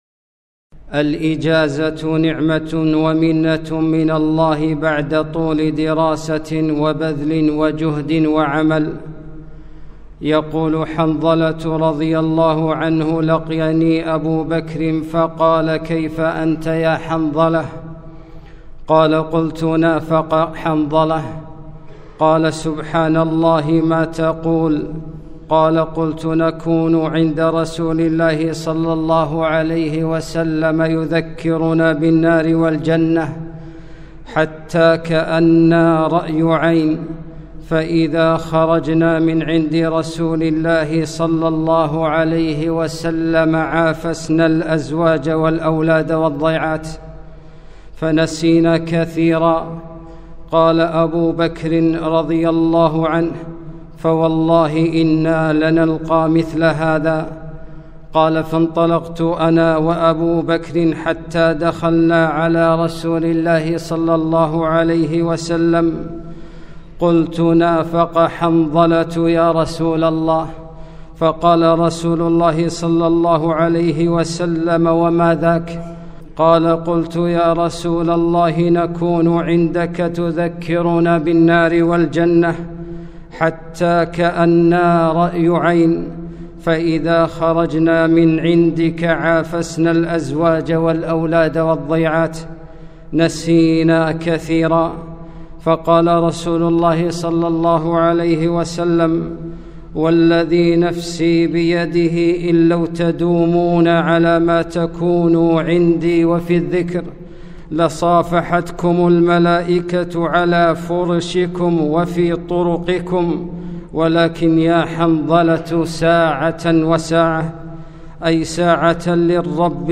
خطبة - الإجازة الصيفية